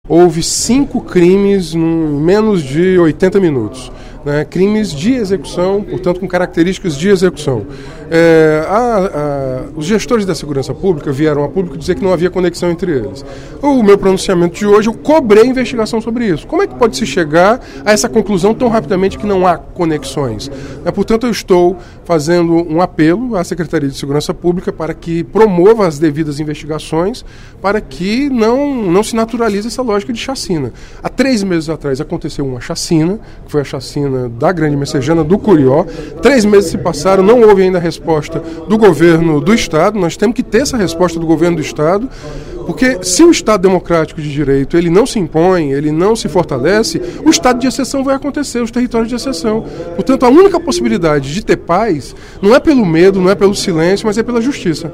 O deputado Renato Roseno (Psol) enfatizou, durante o primeiro expediente da sessão plenária desta terça-feira (16/02), o assassinato de 30 pessoas em dois dias no Ceará, segundo a Secretaria de Segurança Pública e Defesa Social (SSPDS).